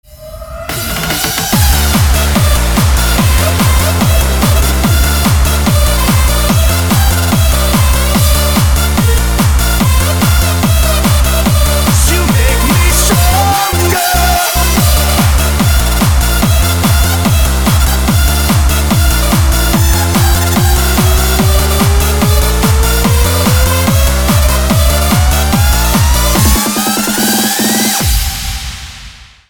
• Качество: 320, Stereo
мужской голос
ритмичные
громкие
dance
электронная музыка
Eurodance
Euro House